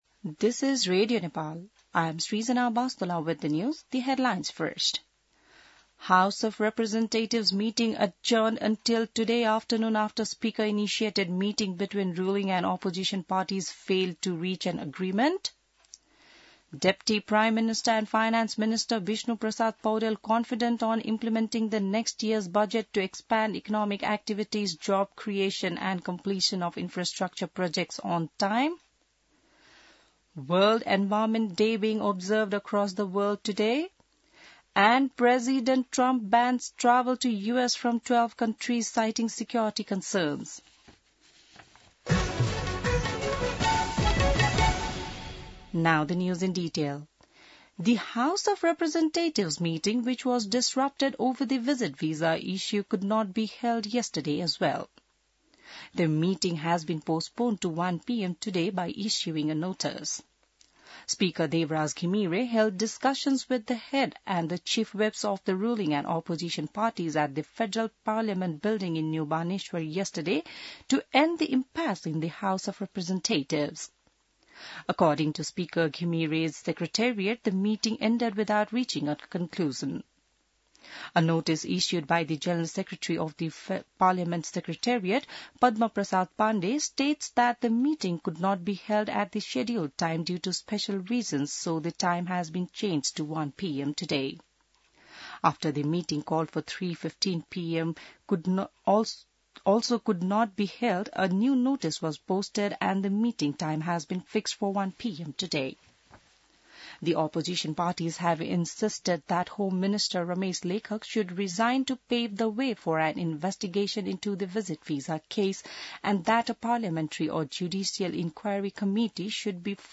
बिहान ८ बजेको अङ्ग्रेजी समाचार : २२ जेठ , २०८२